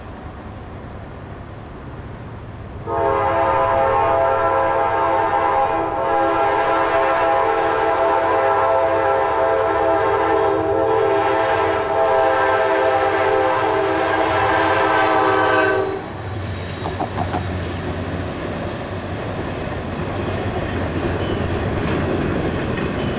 Doppler Shift